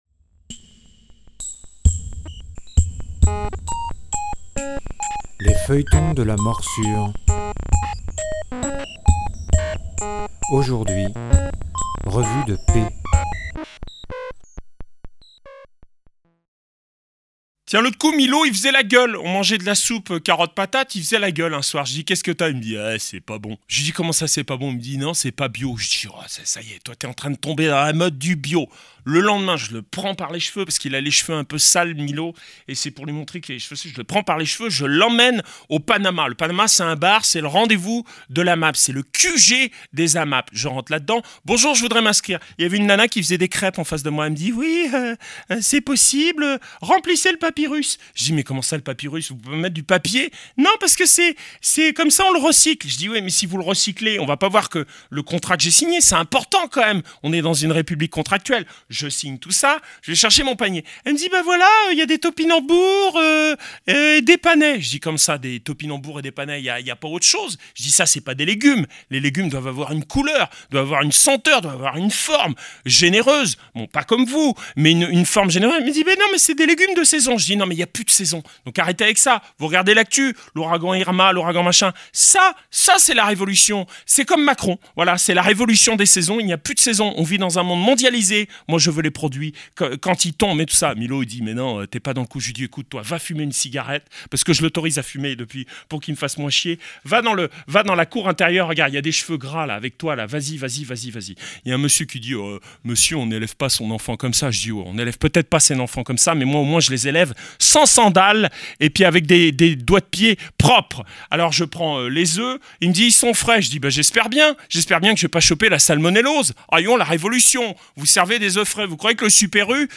Les créations radiophoniques improvisées, la tragicomédie humaine de la Morsure…